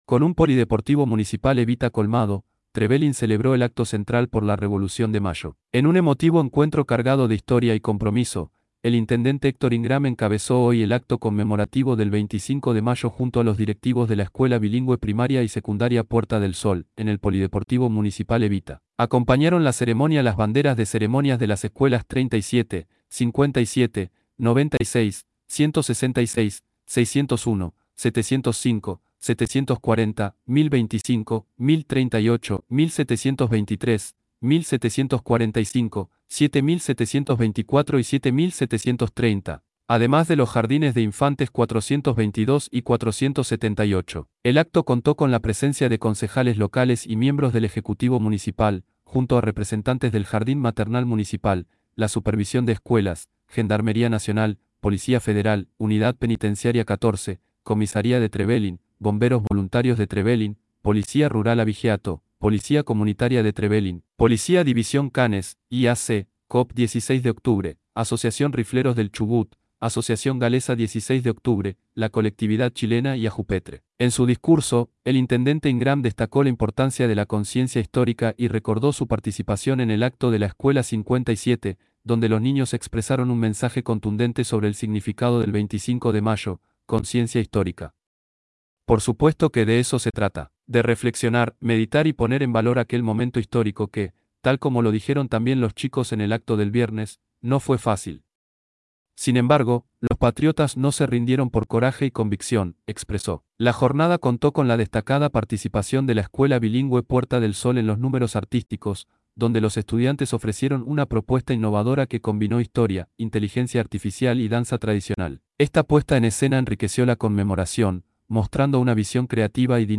CON UN POLIDEPORTIVO MUNICIPAL EVITA COLMADO, TREVELIN CELEBRÓ EL ACTO CENTRAL POR LA REVOLUCIÓN DE MAYO
acto_central_por_la_revolucion_de_mayo.mp3